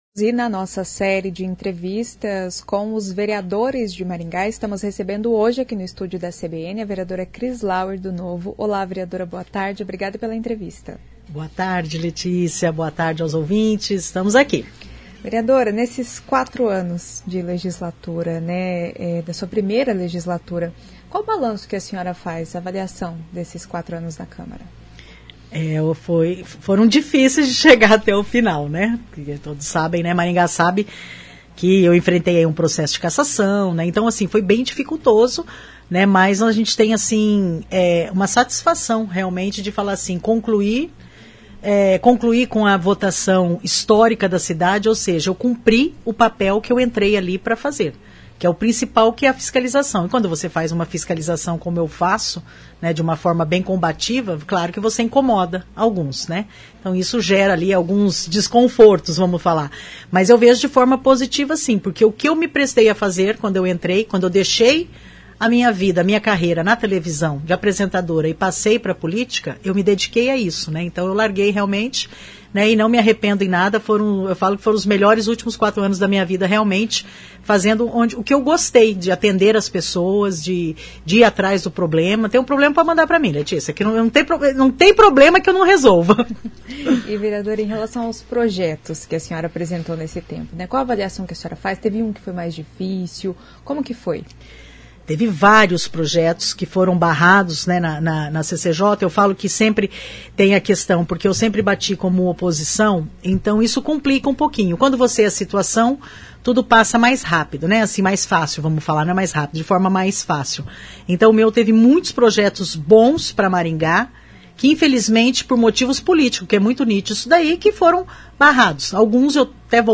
Nesta entrevista a vereadora Cris Lauer (Novo), fez um balanço da primeira legislatura como vereadora.